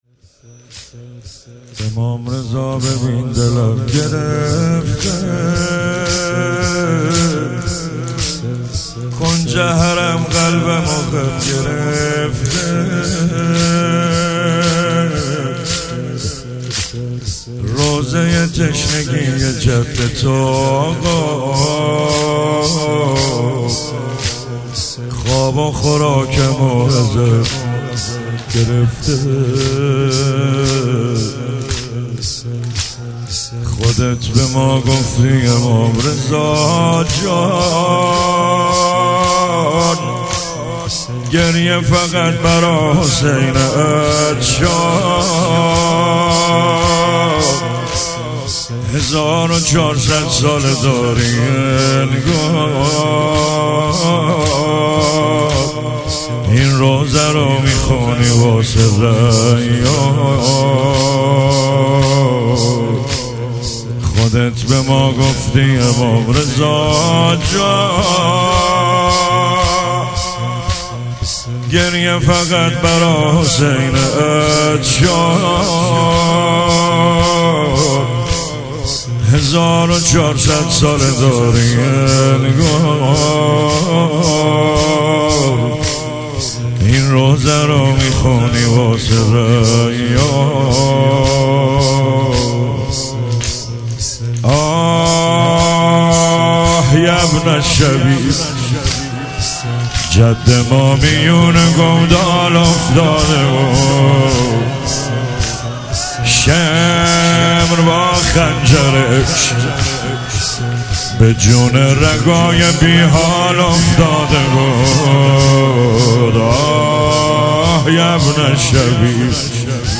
با رادیو عقیق همراه شوید و مداحی امام رضا ببین دلم گرفته را به صورت کامل بشنوید.
در مجلس هئیت علمدار مشهد الرضا (ع)
مداحی به سبک زمینه اجرا شده است.